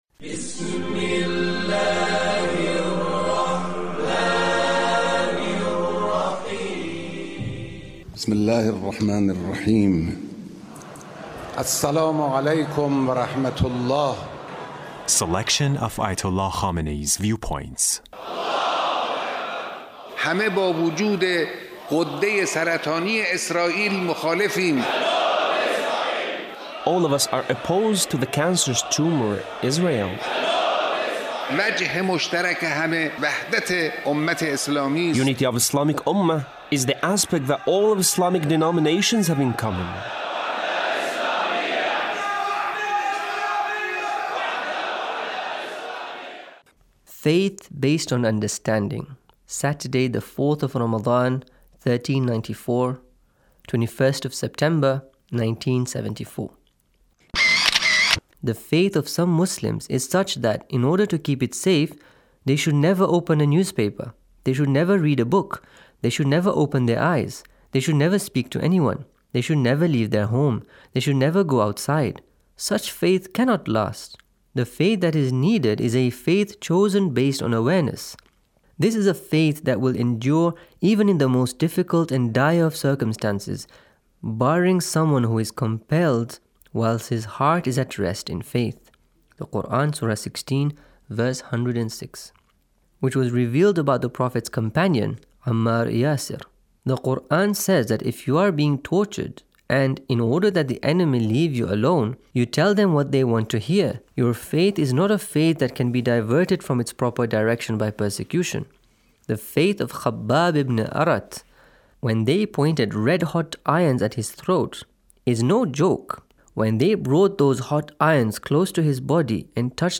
Leader's Speech on Taqwa